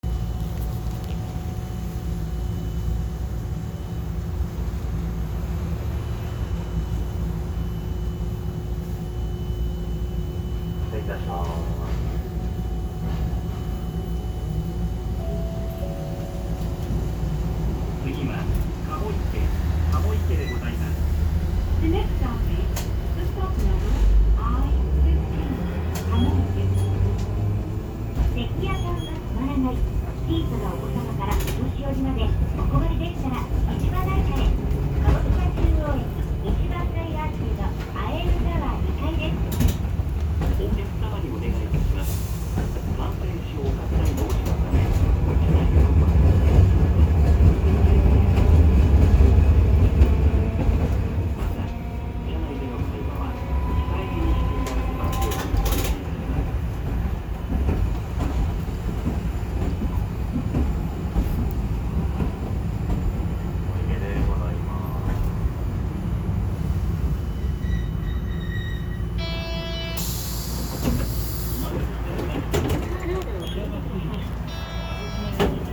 ・9500形走行音
水族館口→朝日通（途中の信号停車まで）（1分17秒：419KB）
ツリカケ式です。昔ながらの走行音を堪能できる半面車内放送はよく聞こえません。